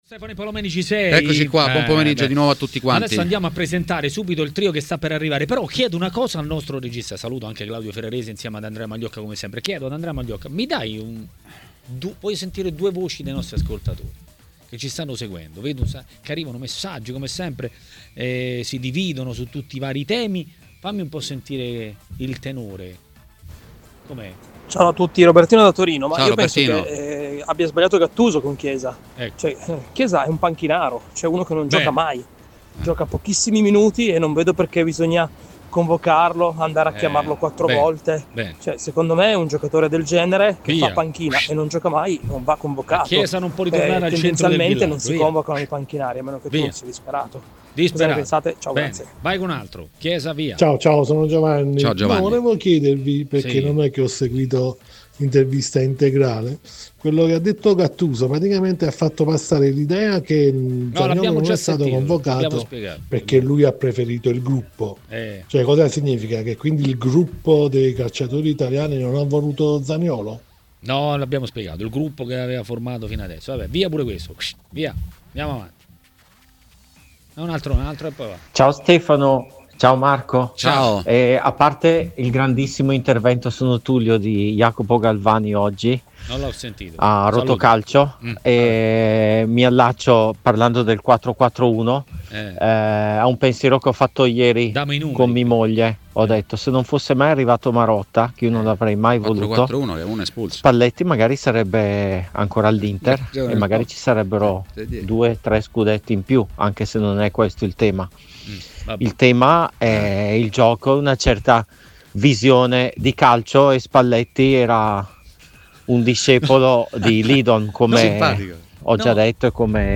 A TMW Radio, durante Maracanà, è arrivato il momento dell'ex calciatore Massimo Orlando.